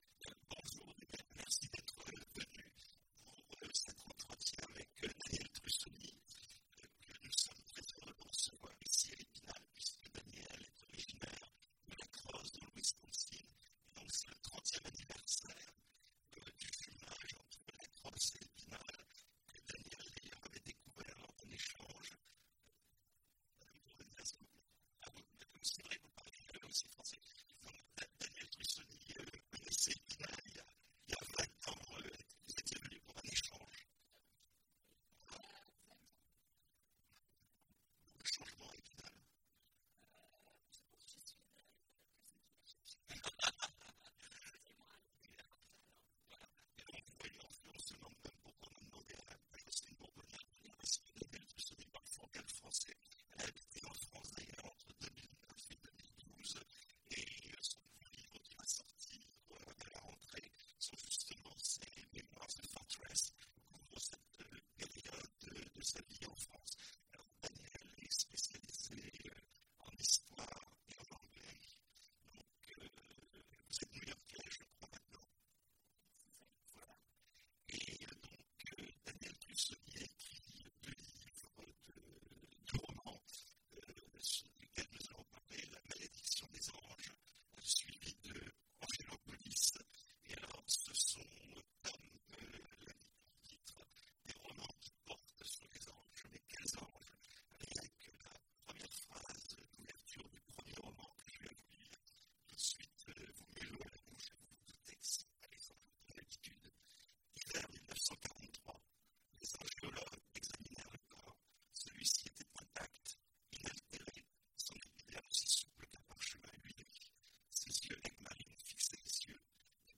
Imaginales 2016 : Entretien avec… Danielle Trussoni
Danielle Trussoni Télécharger le MP3 à lire aussi Danielle Trussoni Genres / Mots-clés Rencontre avec un auteur Conférence Partager cet article